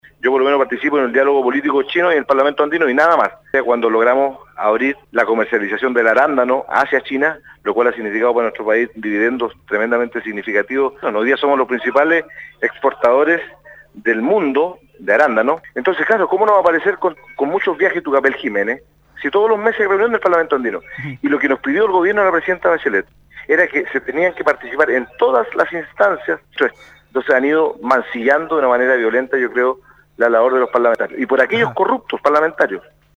En conversación con el programa Haciendo Ciudad de Radio Sago, el Diputado Socialista, Fidel Espinoza, salió al paso de los cuestionamientos en torno al debate en torno a al aporte de los viajes y viáticos de los parlamentarios al exterior, donde él, junto a otros cuatro congresistas, concentran el 30% de los vuelos del total de la cámara. En esta línea, el diputado defendió los viajes efectuados por su persona, como por ejemplos los efectuados a China, donde fue enfático que el resultado de aquello fue haber posicionado a Chile a nivel mundial.